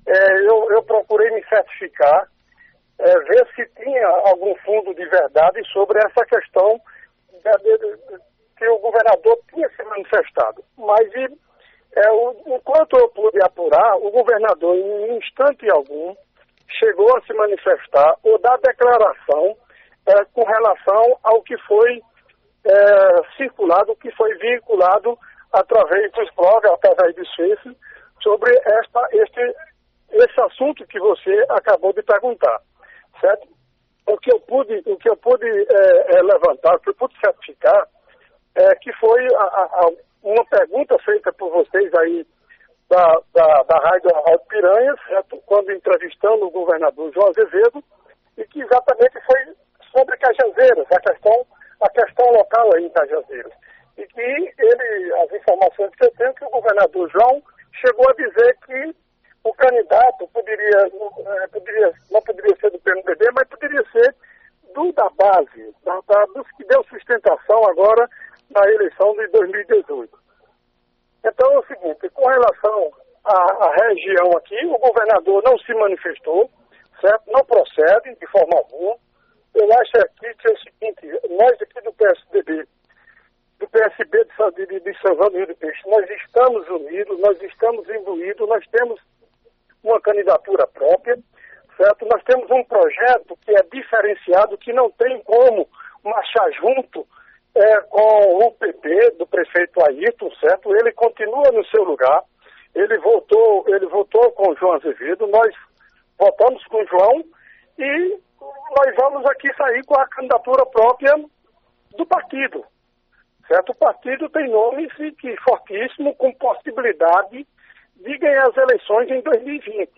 O ex – vereador do município de São João do Rio do Peixe, Zé Ivan (PSB), em entrevista nesta terça- feira(02) ao programa Rádio Vivo da Alto Piranhas, negou informações que foram divulgadas através das redes sociais de que o governador João Azevedo(PSB), quando esteve na última semana em São João do Rio do Peixe teria afirmado apoio ao candidato do prefeito Airton Pires, fato que foi desmentido pelo ex – vereador Zé Ivan.